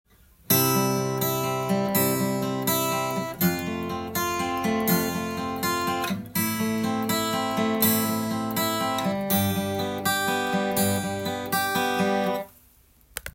【ピック＆指弾きハイブリット練習２】
①は毎小節１拍目にピックでベース音を弾きながら
１弦を指で弾いていきます。
コードがC/F/Am/Gとシンプルですので